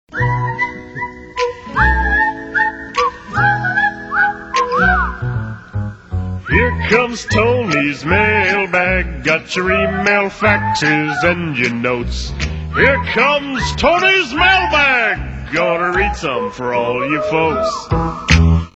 Who sings the Mailbag theme?
Nice pipes, huh?